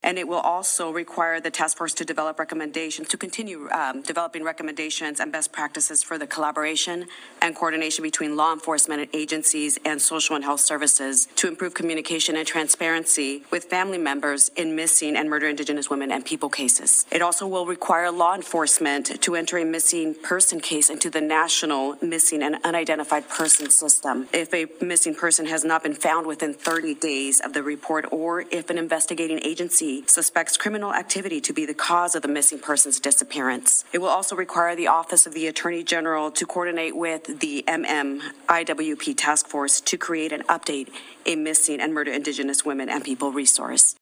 Washington State legislators held a press conference today at the State Capitol to discuss domestic violence proposals under consideration by the 2023 Legislature.